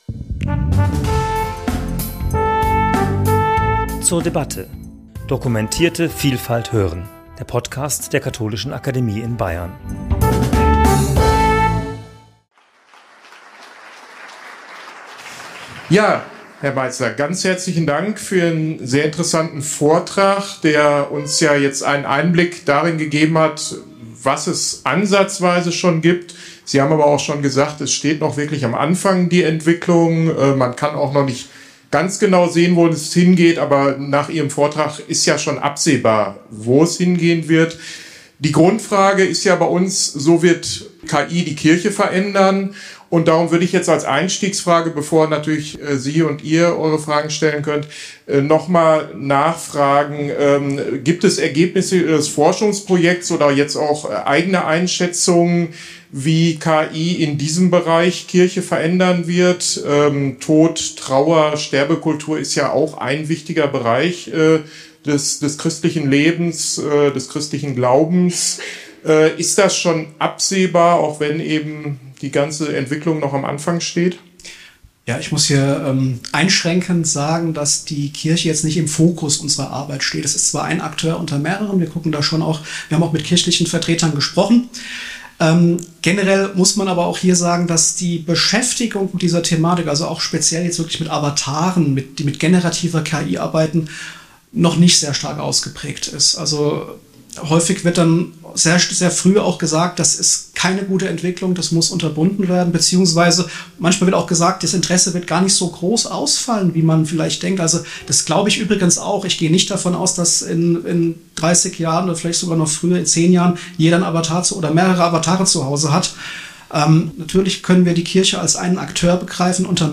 Gespräch zum Thema 'So wird KI den Umgang mit Tod, Trauer und Erinnerung verändern' ~ zur debatte Podcast